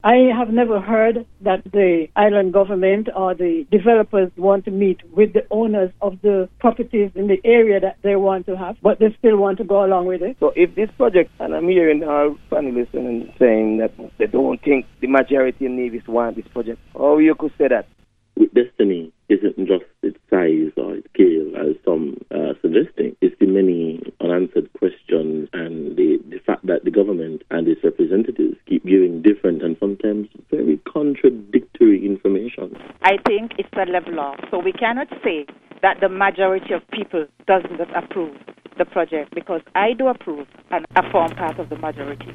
During this week’s Let’s Talk Program, callers voiced their opinions on the proposed development: